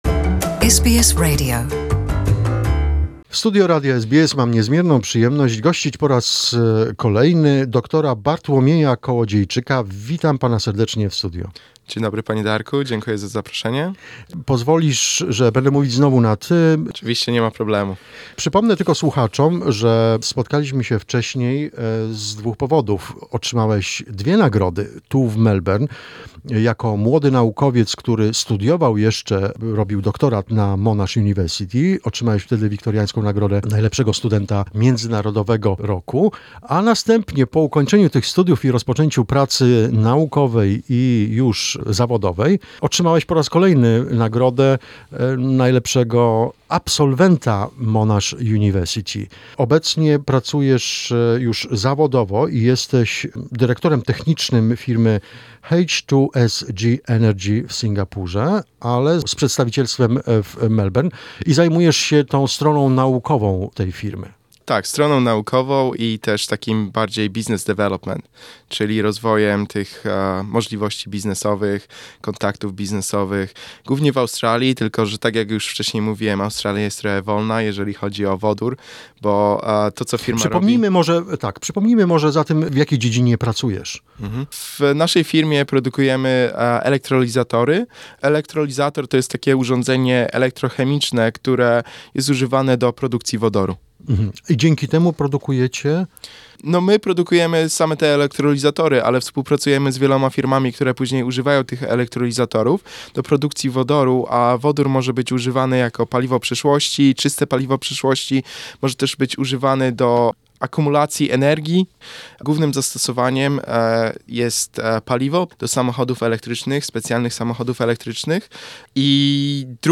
This is part 1 of the interview.